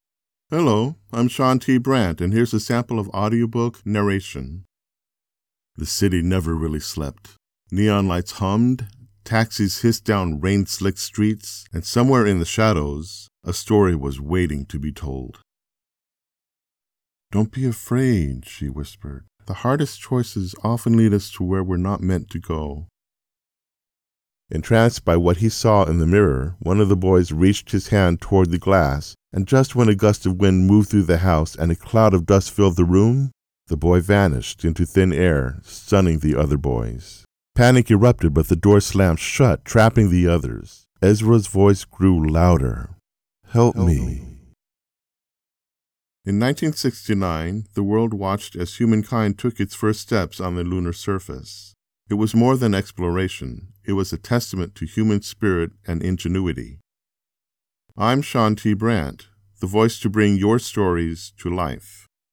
AUDIO BOOK DEMO
Standard American Male accent